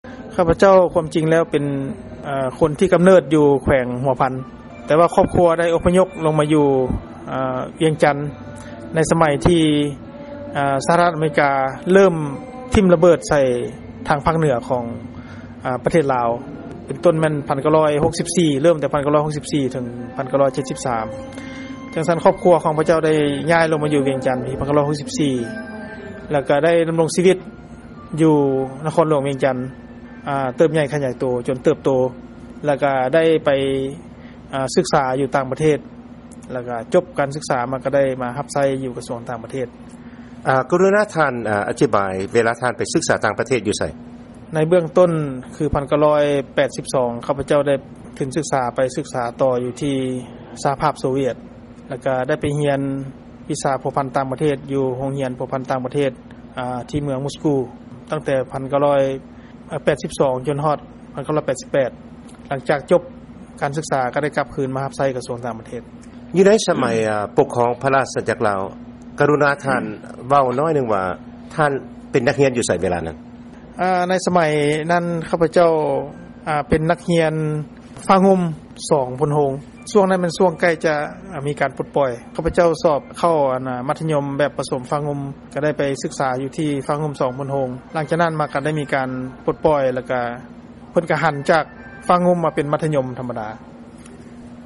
ຟັງການສຳພາດ ພະນະທ່ານ ເອກອັກຄະລັດຖະທູດ ໄມ ໄຊຍະວົງ ຈາກ ສປປ ລາວ